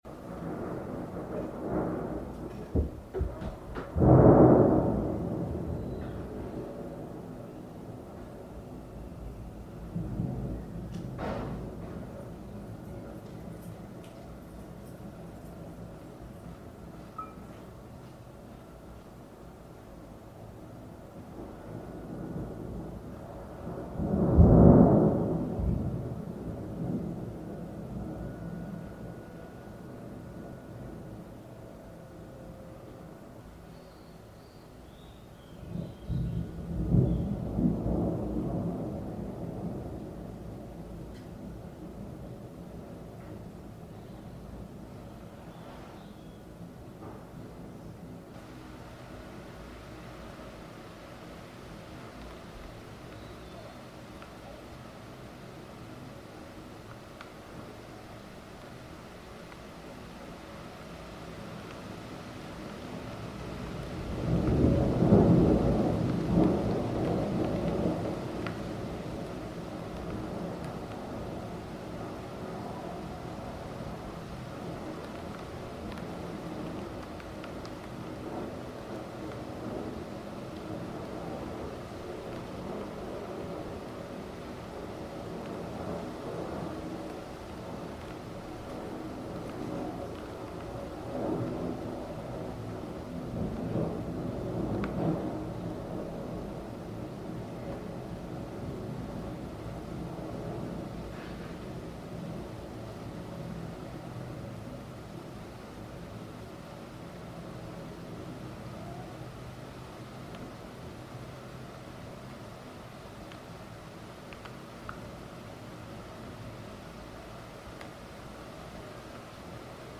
Tuesday Thunder and Rain 18 May 2021
Highlights from an afternoon thunderstorm on 18 May 2021, recorded from my bathroom window.